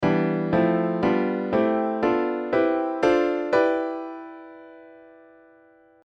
Chords Built On Phrygian‘s Scale Degrees
AFM_36_Phrygian.mp3